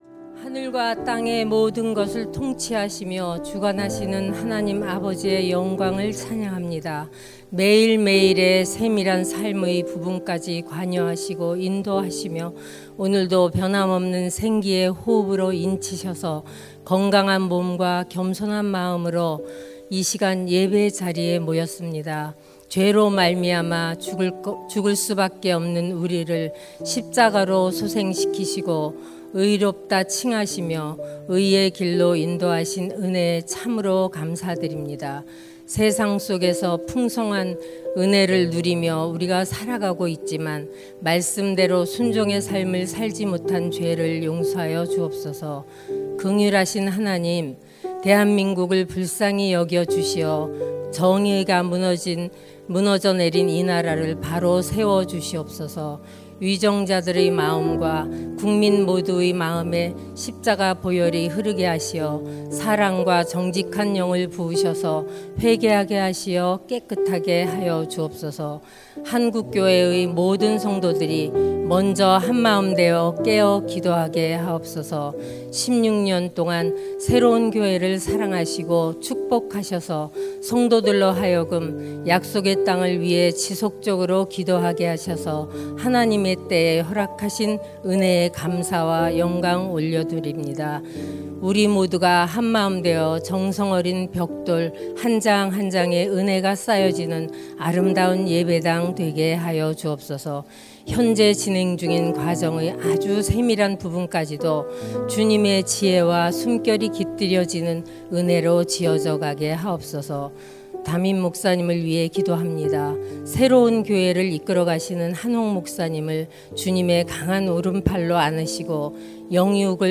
2025-09-25 새벽기도회
> 설교